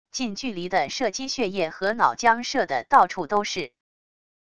近距离的射击血液和脑浆射得到处都是wav音频